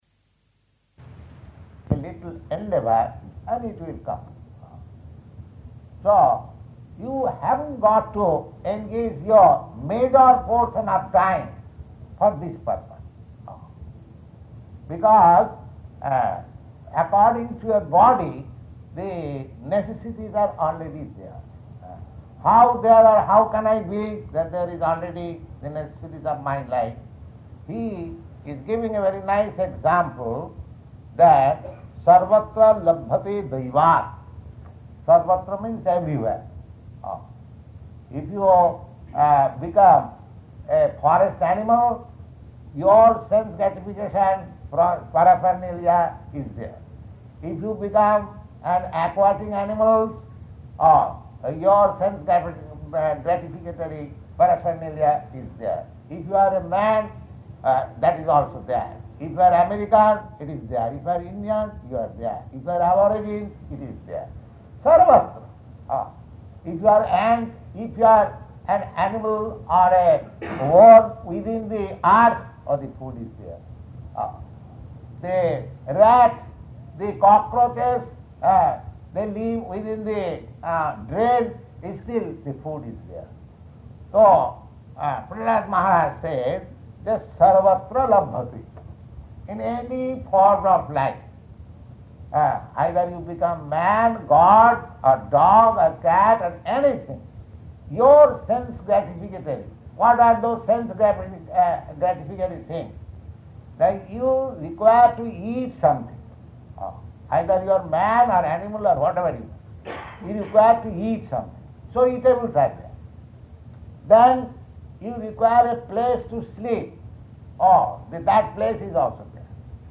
Śrīmad-Bhāgavatam 7.6.3-4 --:-- --:-- Type: Srimad-Bhagavatam Dated: March 9th 1968 Location: San Francisco Audio file: 680309SB-SAN_FRANCISCO.mp3 [incomplete lecture] Prabhupāda: ...a little endeavor, and it will come.